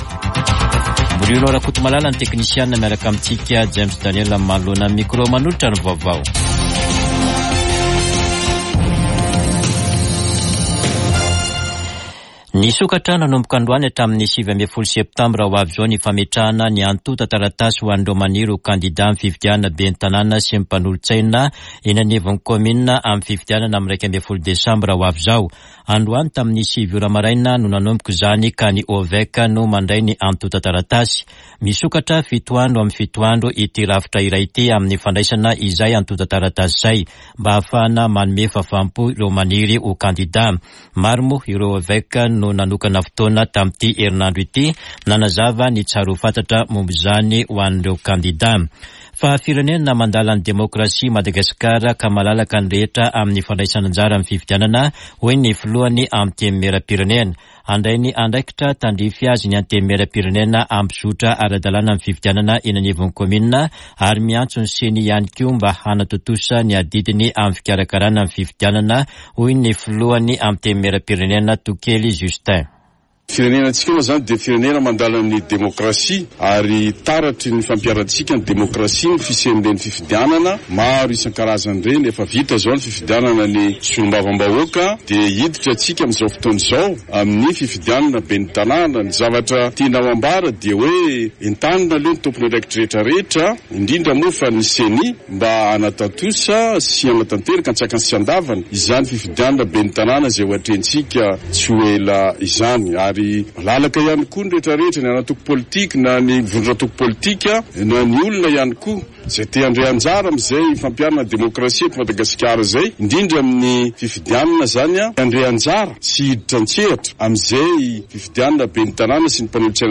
[Vaovao antoandro] Alakamisy 5 septambra 2024